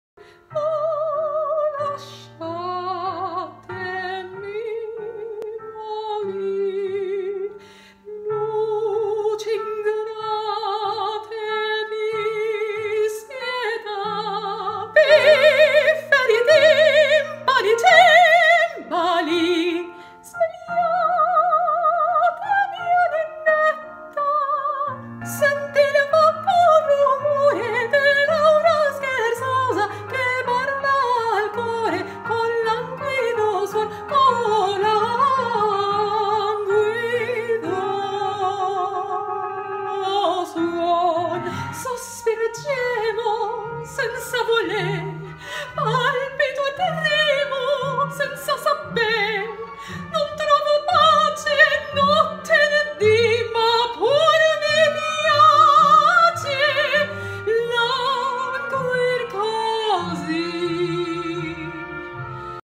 Female
Singing
Opera Singing - Italian
0630Classical_Song_Reel.mp3